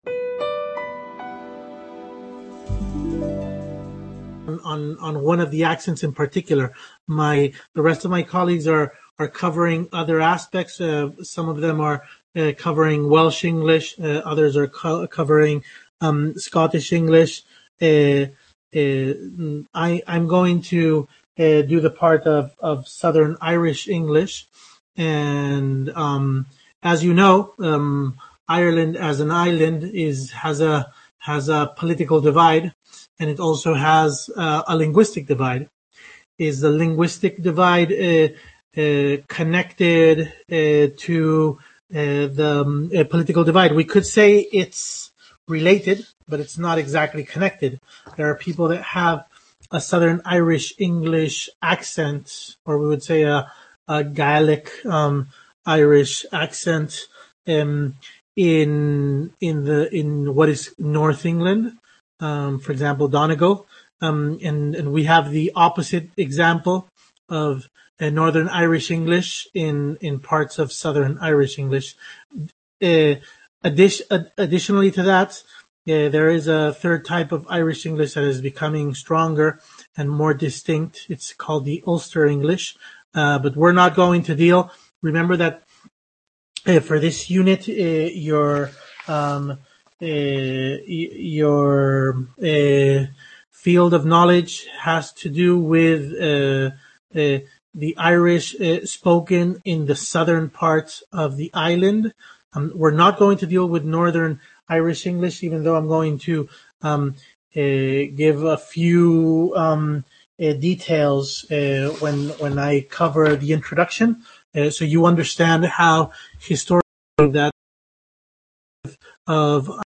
Intercampus Webconference held on March 26th